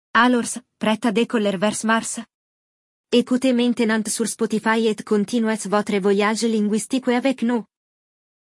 Nesta aula de áudio, você aprenderá novas expressões e expandirá seu vocabulário enquanto acompanha um diálogo totalmente em francês.